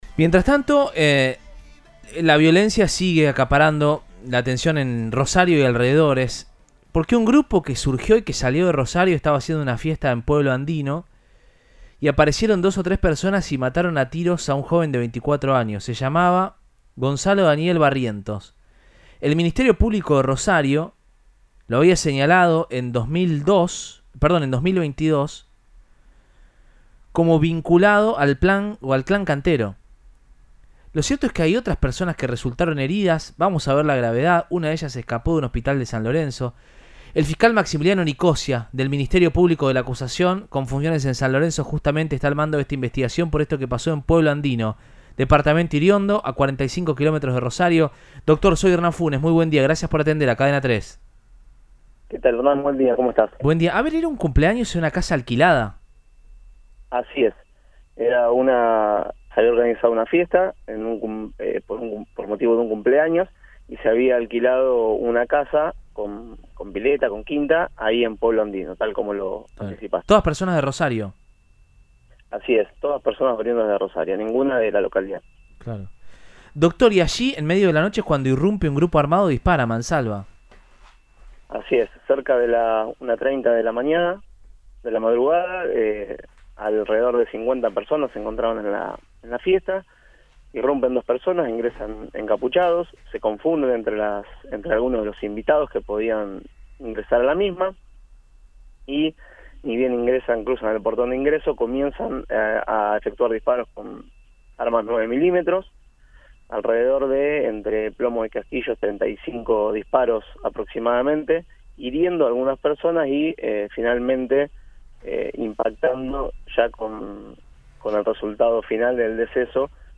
En diálogo con Radioinforme 3, por Cadena 3 Rosario, el fiscal Maximiliano Nicosia comentó que se trataba de “una fiesta por un cumpleaños” con “todos invitados de Rosario”.